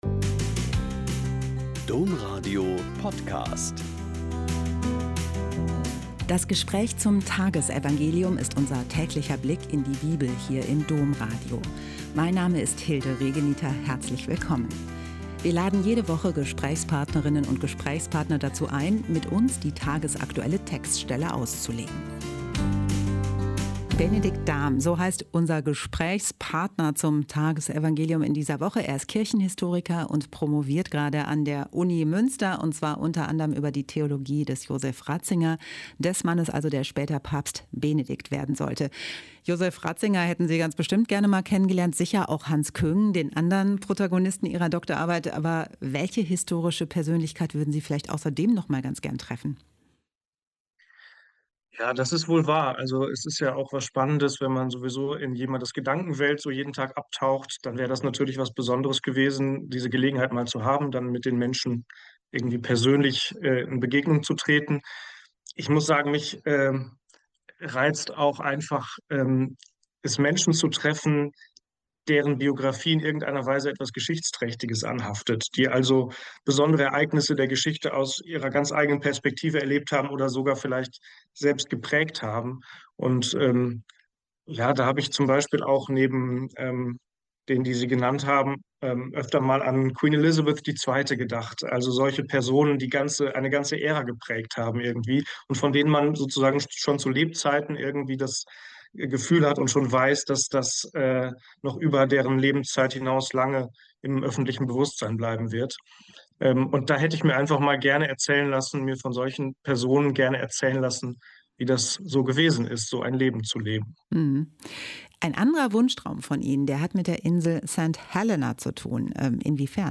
Mt 13,44-46 - Gespräch